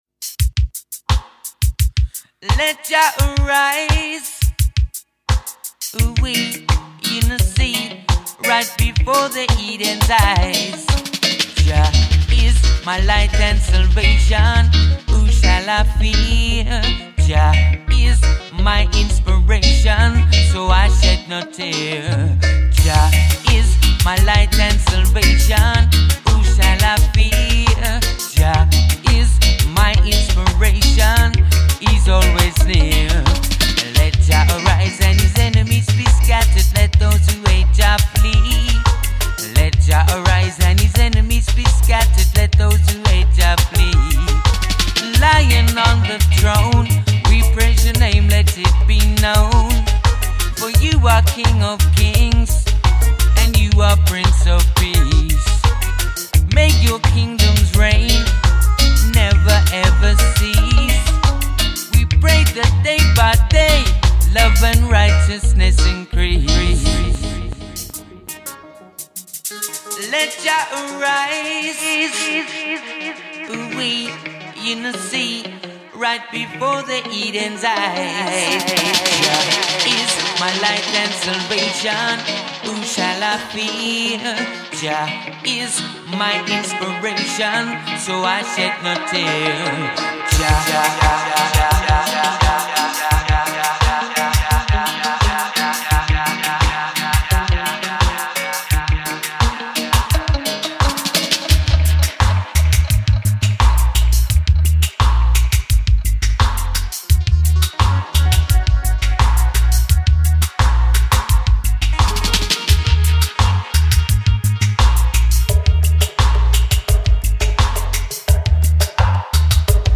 7inch Vinyl
Vocals